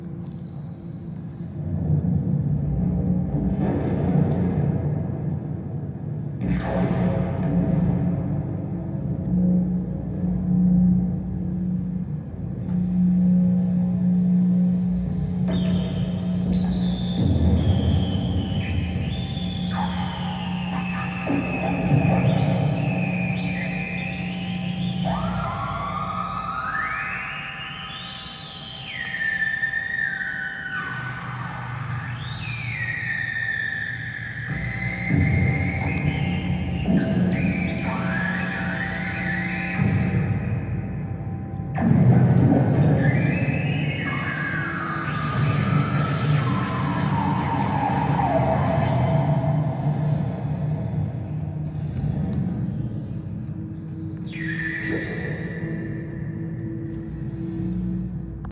Mark 0-2 Another walkman style recorder, an old GE from Ebay. The entrails on this player are exposed to allow for hand-bending, and can be adjusted via finger pressure and position on the board.